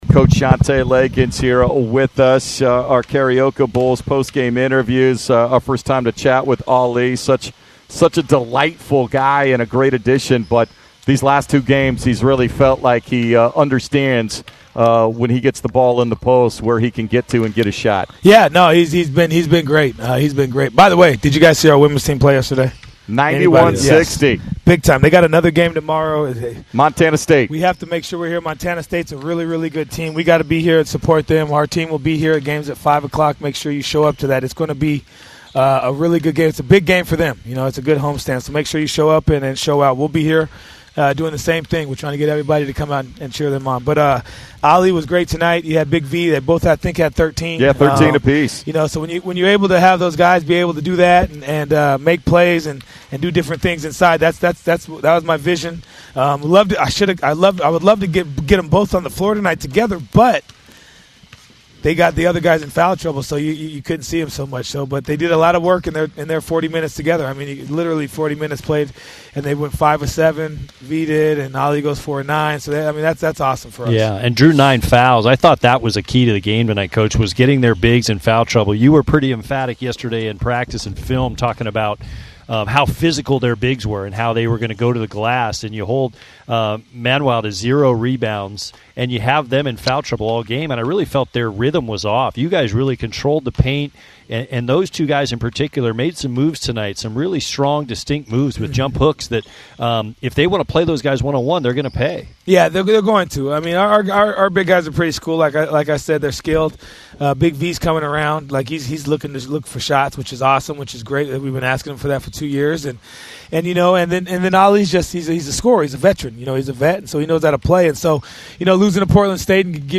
Men's Basketball Radio Interviews